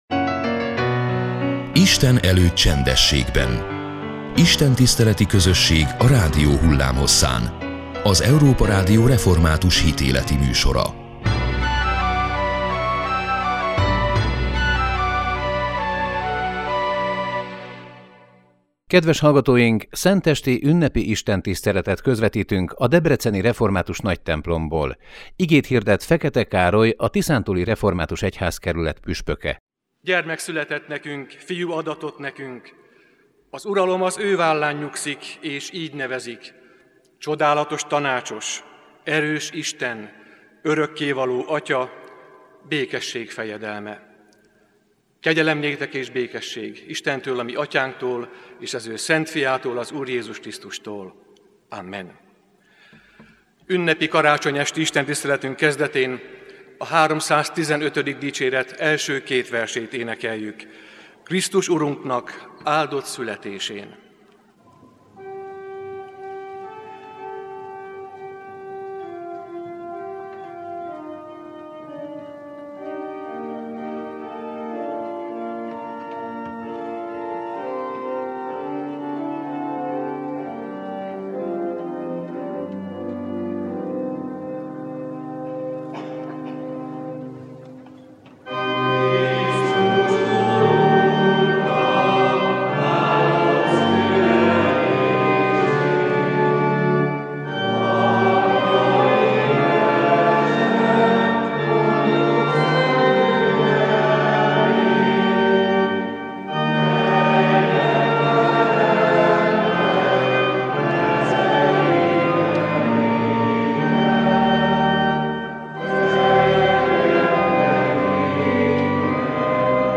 Szentesti ünnepi istentiszteletet közvetítettünk a debreceni református Nagytemplomból. Igét hirdetett Fekete Károly, a Tiszántúli Református Egyházkerület püspöke.